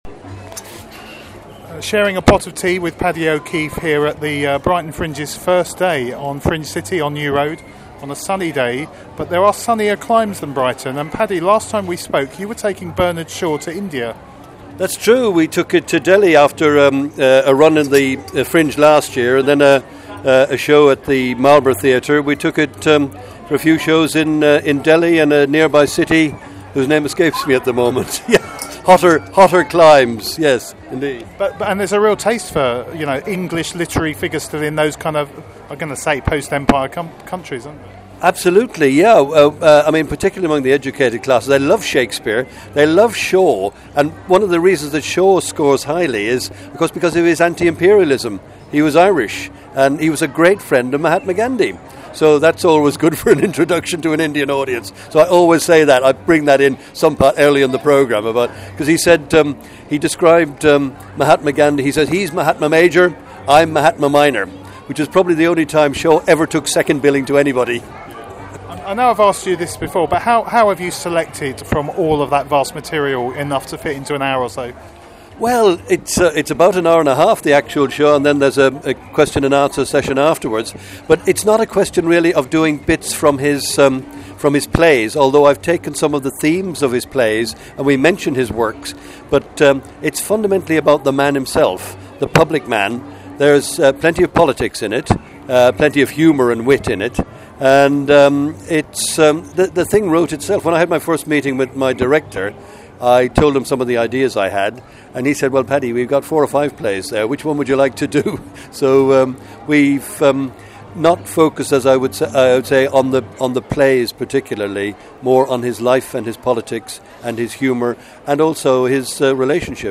Brighton Audio 2014